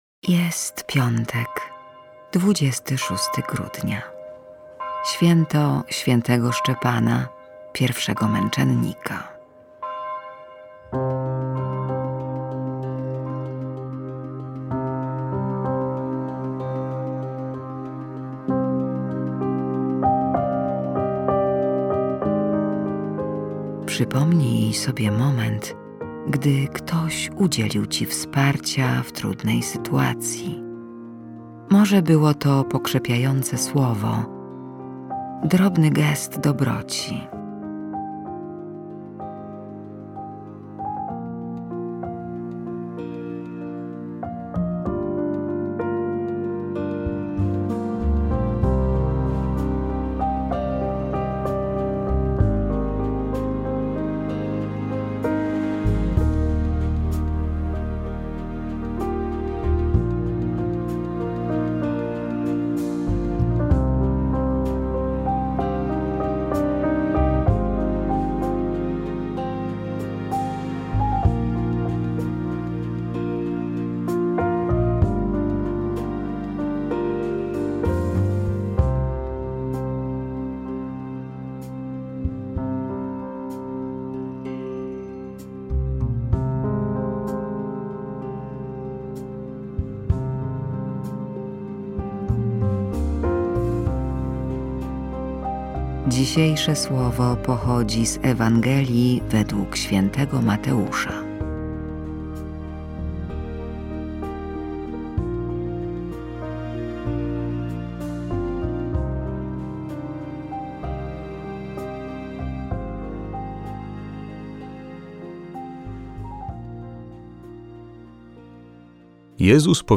Koncert kolęd – 17.01.2021 r.